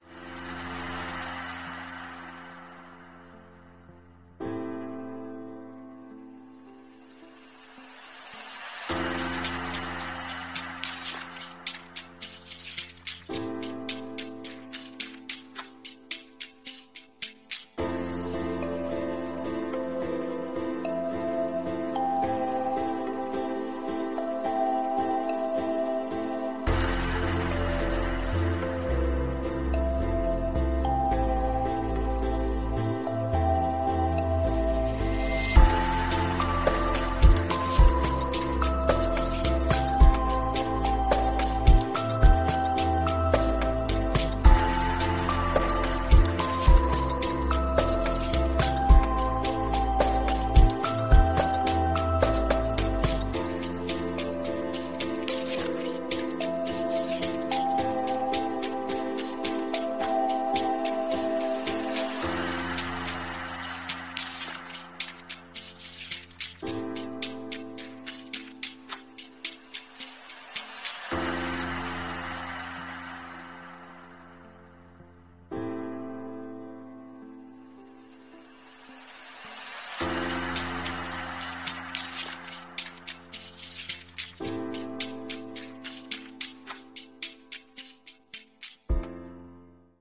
【イメージ】 夏の初めのある日、微風が海面を撫で、そのさざめきが心地よいリズムを奏でています。